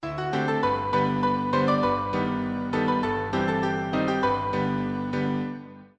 Augmented Triad
No. III., mm. 78-82 (also contains mode mixture iv)